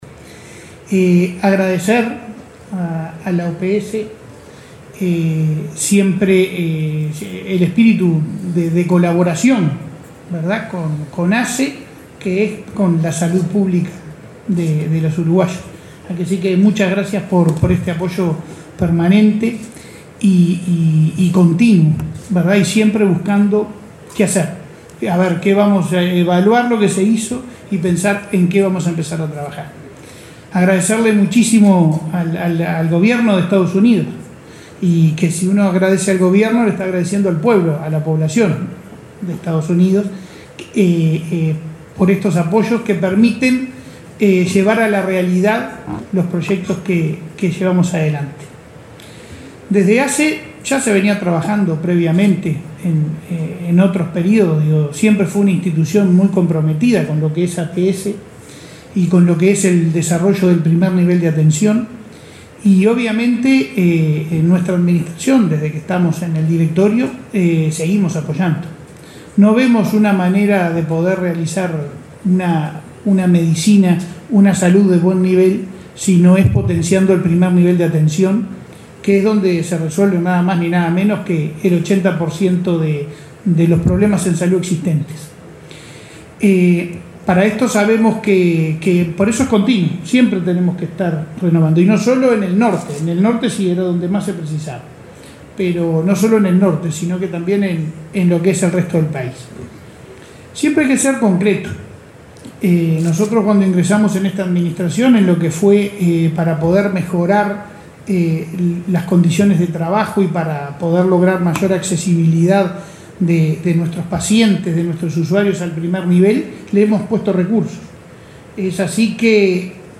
Palabras del presidente de ASSE, Leonardo Cipriani
El presidente de la Administración de los Servicios de Salud del Estado (ASSE), Leonardo Cipriani, participó en el acto de cierre del curso